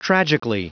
Prononciation du mot tragically en anglais (fichier audio)
Prononciation du mot : tragically